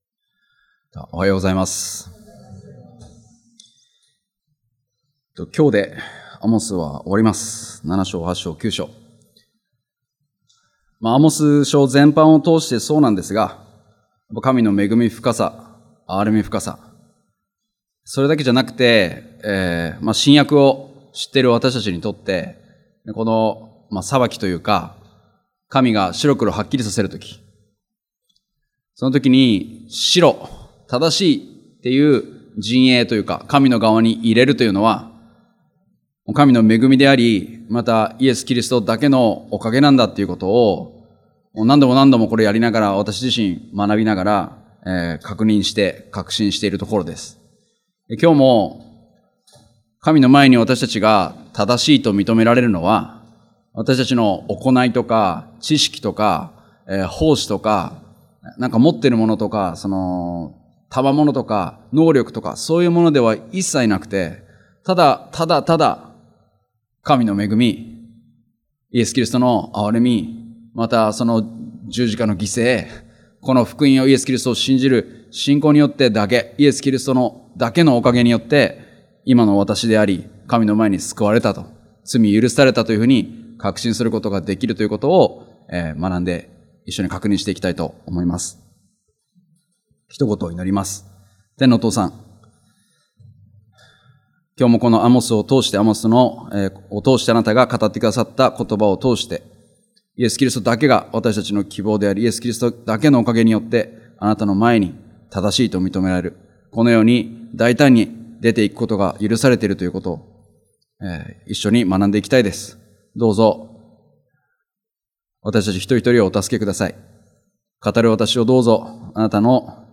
日曜礼拝：アモス書